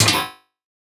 Hit for enemy 1.wav